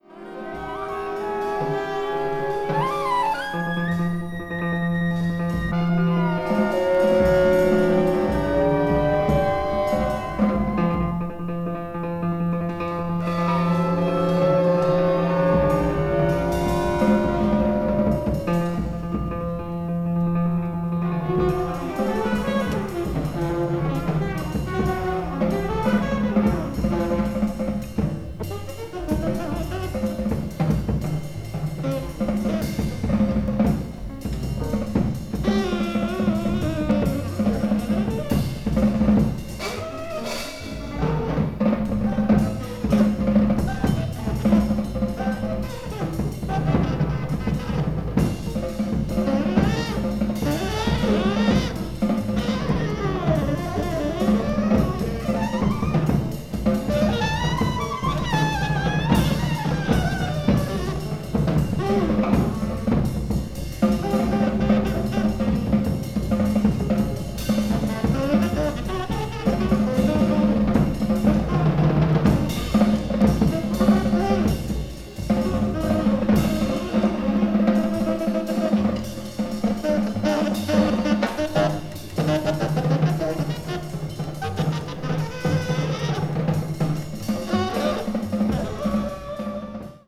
1964年にニューヨークのJudson Hallで行われたライヴのレコーディング音源。